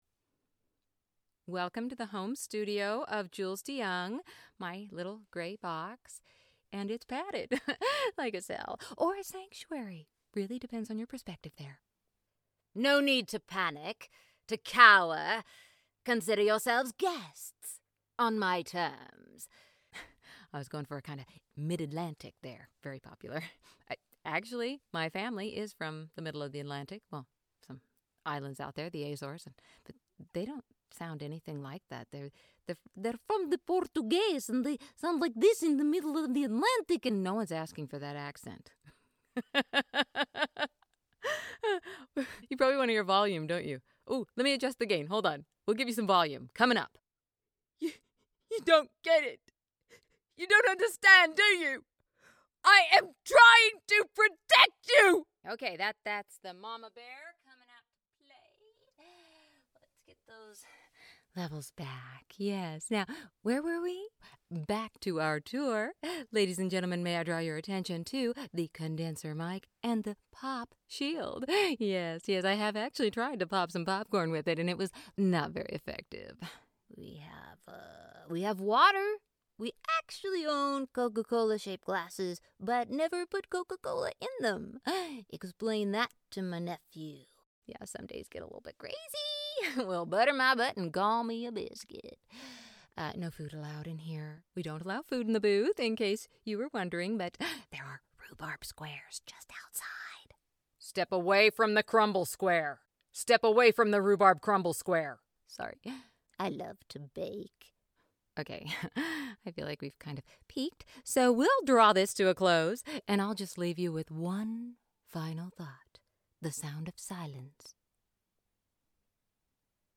USA. Sunny California girl with accents pan-US & globe. Intuitive, responsive with endless characters incl children. Skilled in improv.